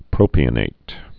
(prōpē-ə-nāt)